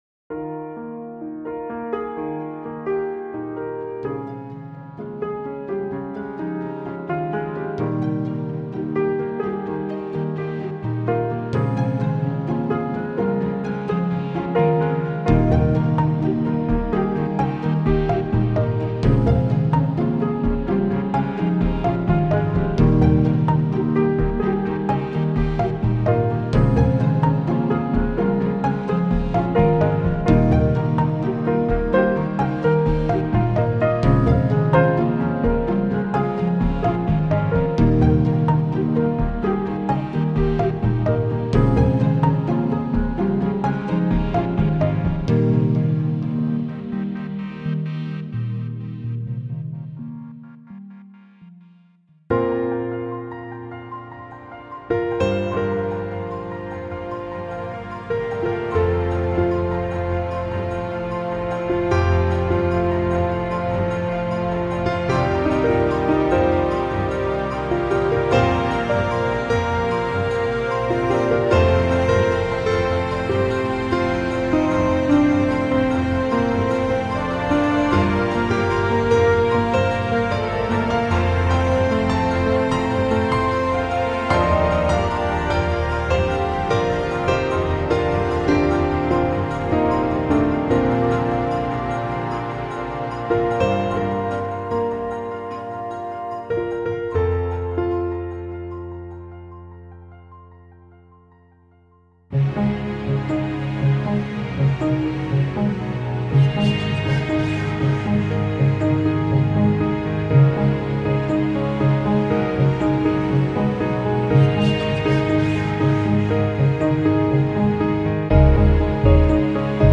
a moving cinematic pack